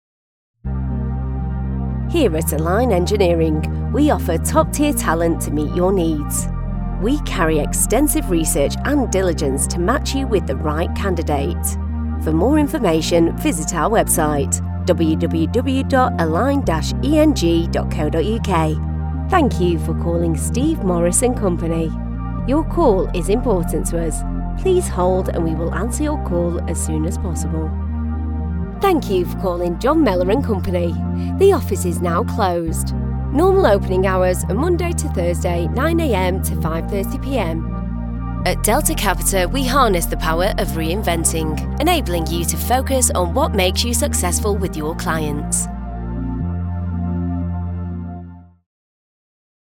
Englisch (Britisch)
Kommerziell, Natürlich, Verspielt, Cool, Warm
Telefonie